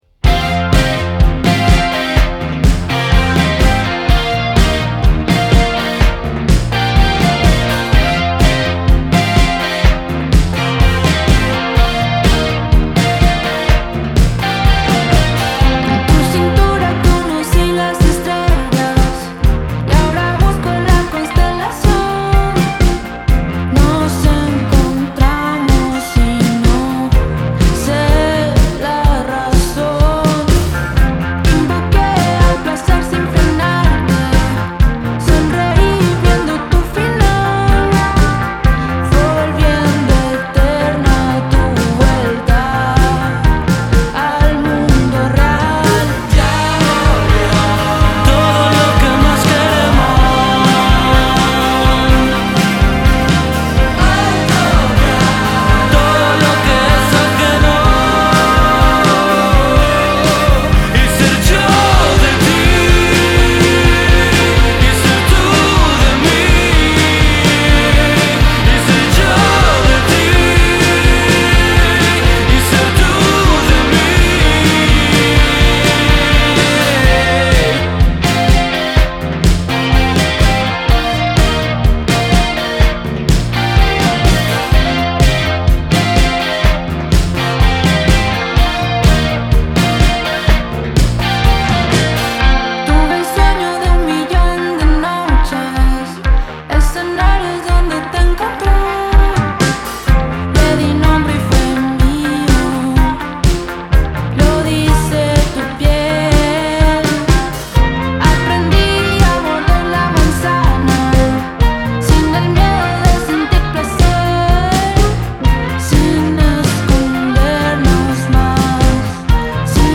un estilo que cruza pop e indie rock
voz y guitarra
teclados y sintetizadores